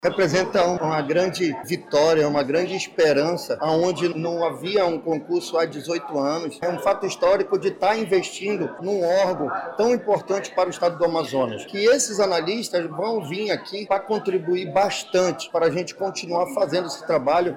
O diretor-presidente do Ipaam, Gustavo Picanço, destaca que o concurso vai fortalecer as instituições e a defesa do Meio Ambiente.